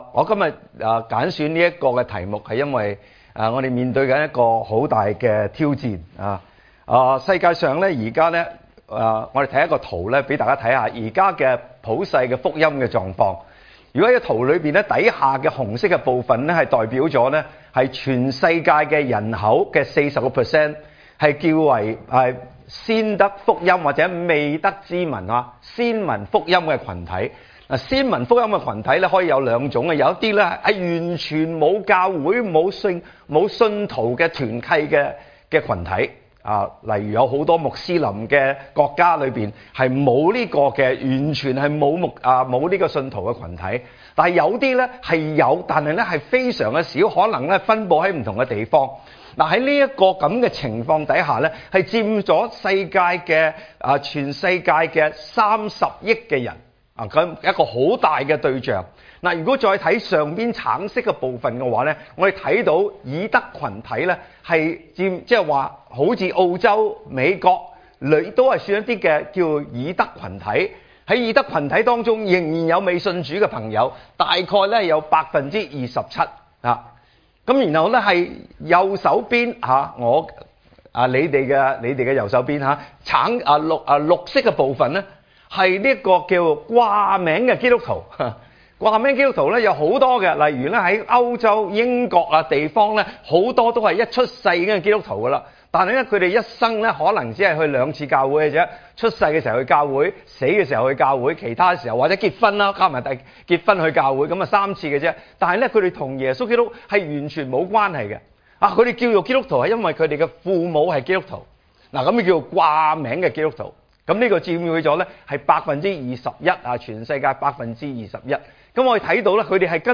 場所：主日崇拜